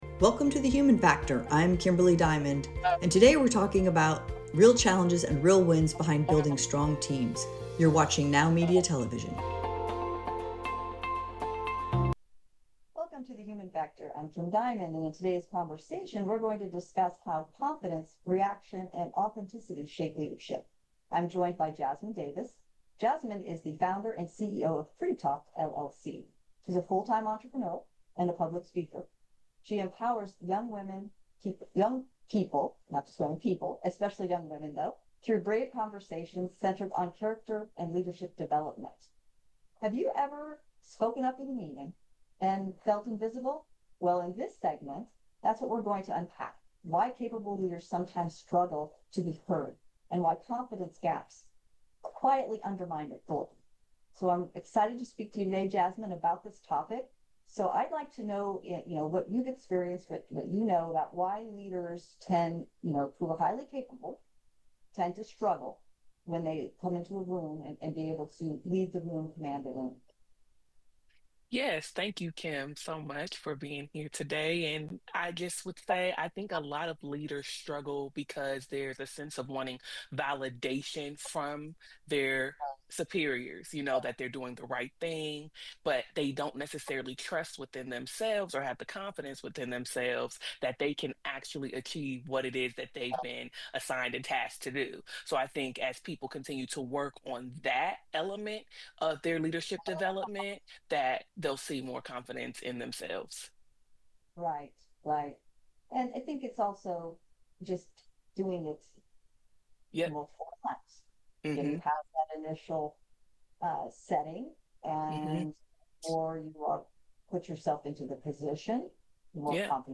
Aired 02-21-26 on Now Media Television, The Human Factor dives into the real challenges behind strong leadership and high-performing teams.
This powerful conversation breaks down the difference between confidence and self-trust, practical strategies for managing emotional reactions at work, and actionable habits leaders can build to strengthen presence and decision-making.